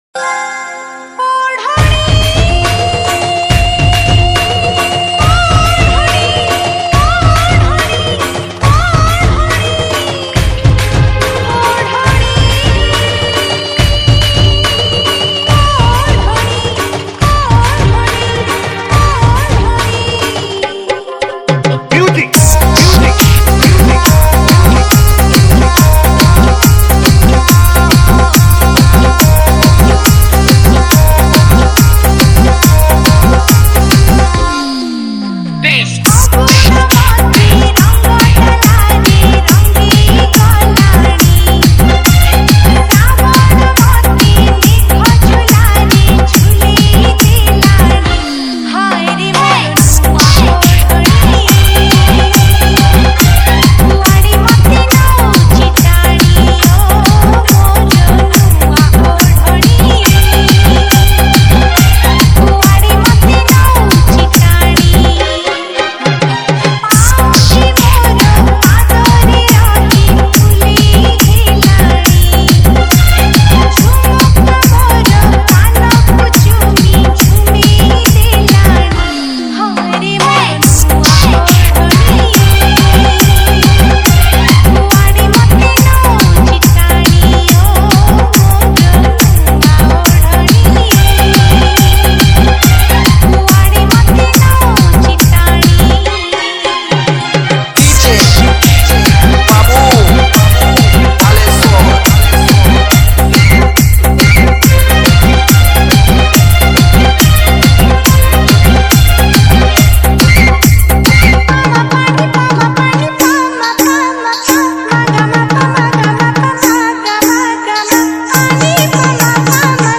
ODIA ROMANTIC DJ REMIX